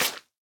Minecraft Version Minecraft Version snapshot Latest Release | Latest Snapshot snapshot / assets / minecraft / sounds / block / sponge / wet_sponge / step4.ogg Compare With Compare With Latest Release | Latest Snapshot